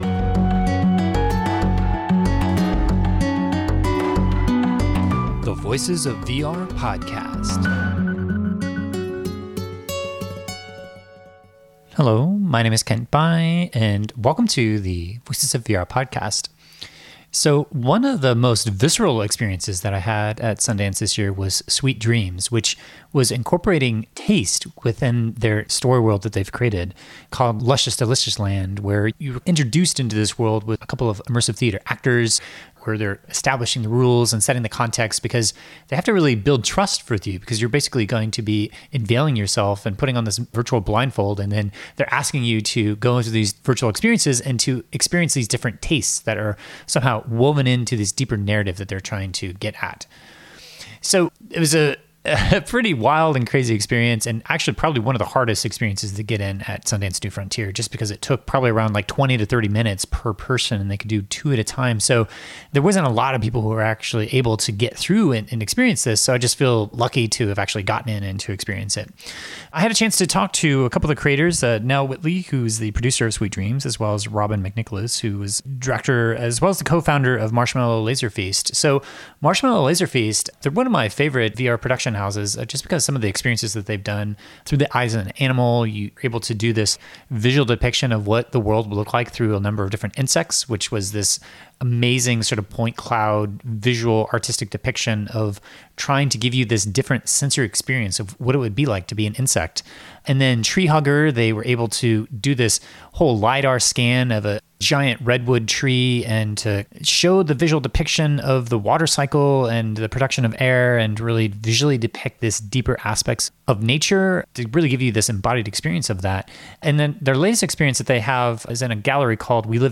What that looks like and how to best do that in the service of a narrative goal turns out to be a VERY hard problem as we explore in this conversation.